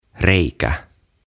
Tuhat sanaa suomeksi - Ääntämisohjeet - Sivu 4
04-Reikä-Hole.mp3